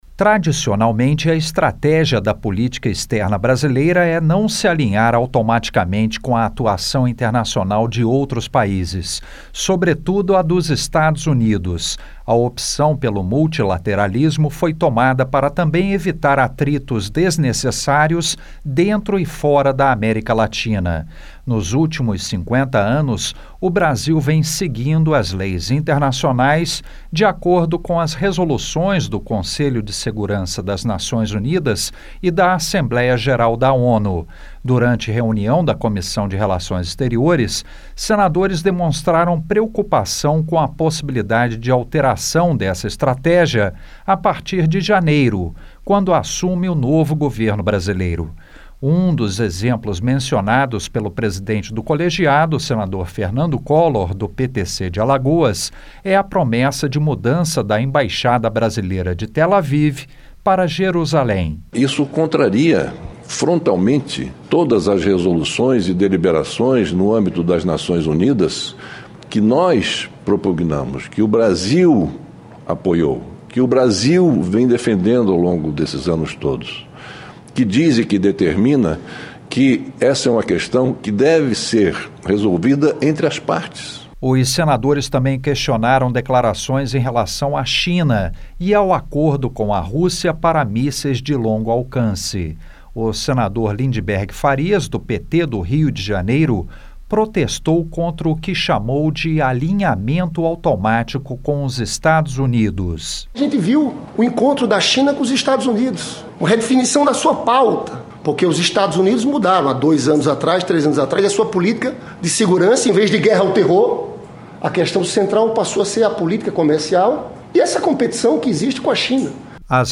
As relações com o Mercosul, com Cuba e com a Venezuela também foram questionadas pelos senadores. A reportagem